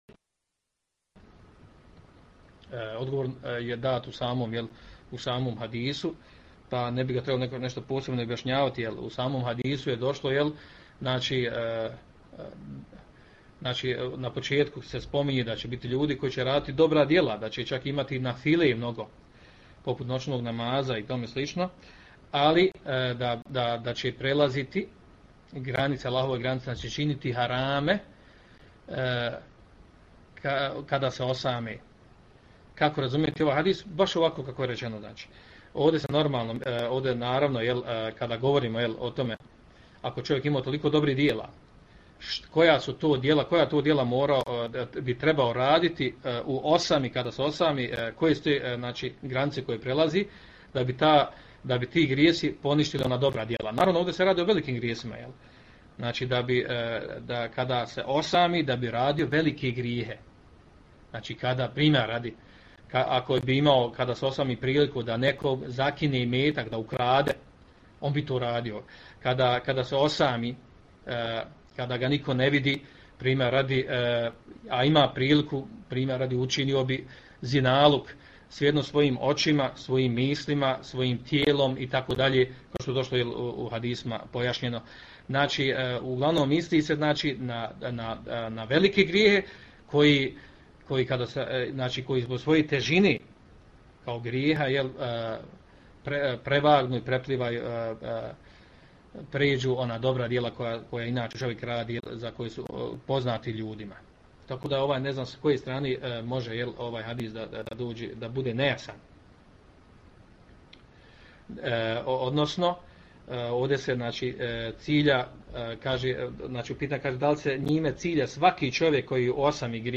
Poslušajte audio isječak iz predavanja